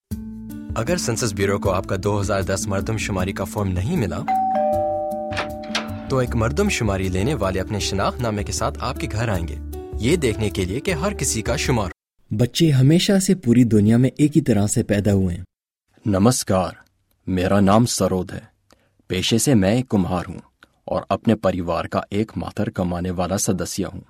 A unique voice that can be tailored to ensure the effective delivery of a variety of tones ranging from authoritative to friendly for a wide range of products and services.
Sprechprobe: Industrie (Muttersprache):